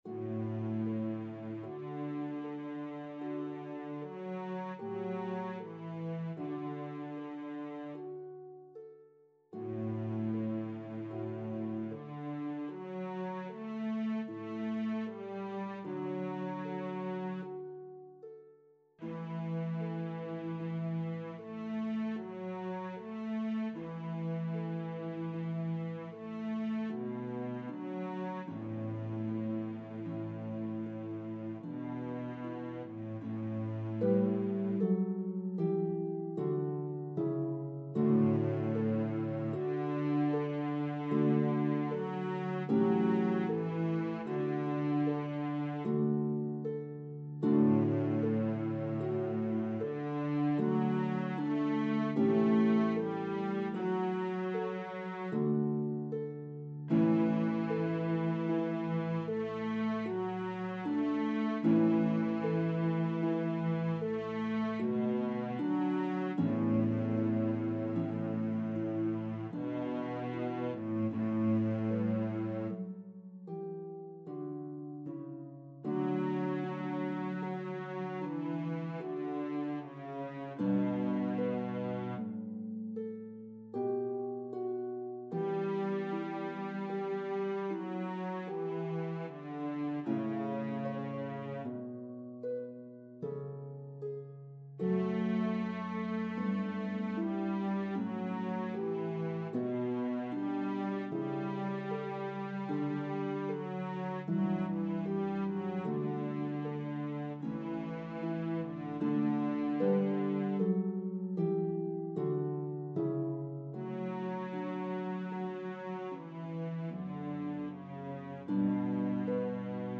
The orchestra parts play beautifully on a pedal harp.